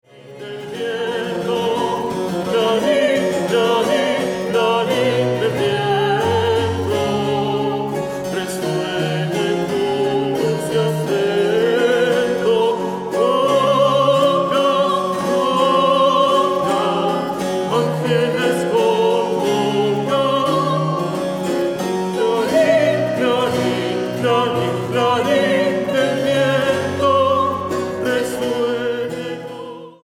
Versiones barroco americano